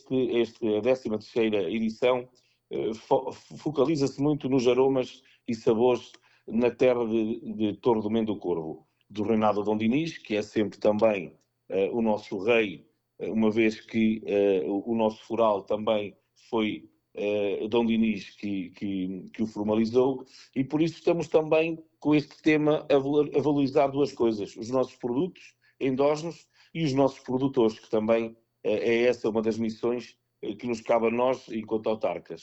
De acordo com o autarca, o tema escolhido centra-se na valorização dos produtos locais e da tradição histórica: